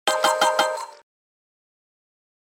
Прикольная мелодия на новой версии Windows